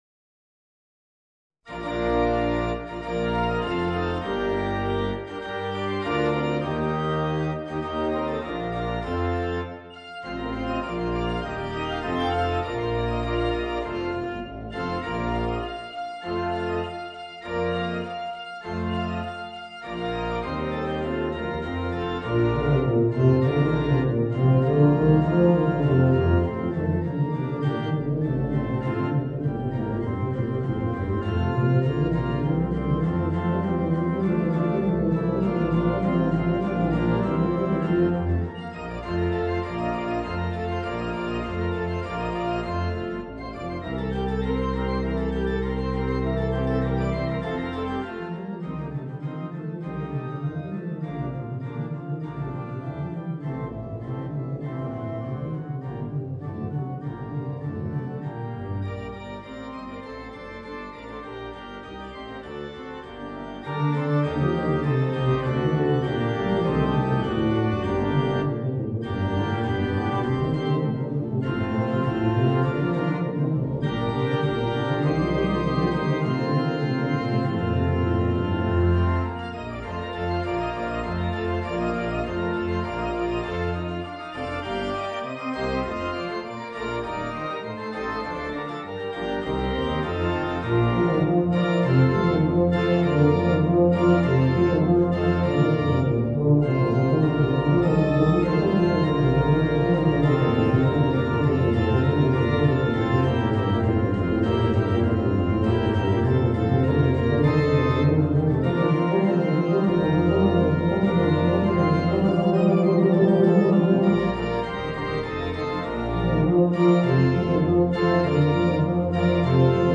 Voicing: Tuba and Organ